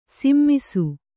♪ simmisu